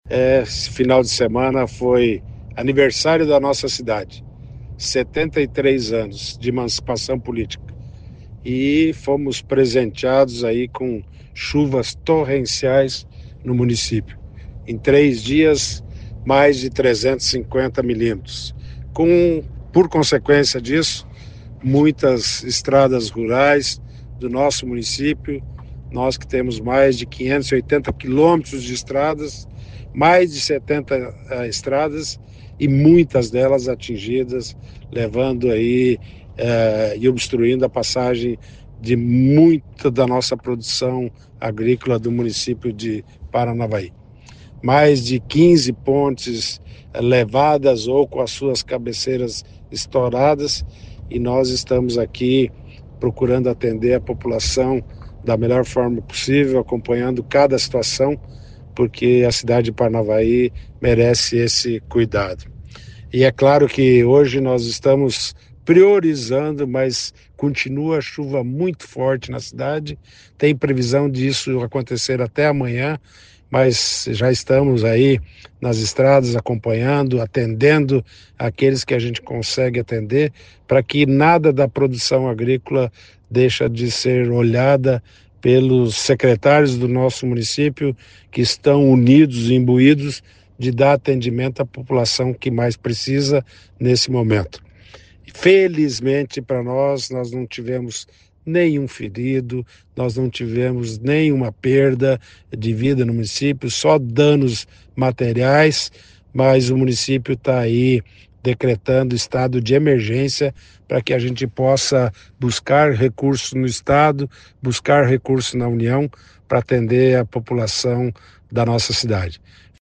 Ouça o que diz o prefeito: